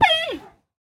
Minecraft Version Minecraft Version snapshot Latest Release | Latest Snapshot snapshot / assets / minecraft / sounds / mob / panda / hurt4.ogg Compare With Compare With Latest Release | Latest Snapshot
hurt4.ogg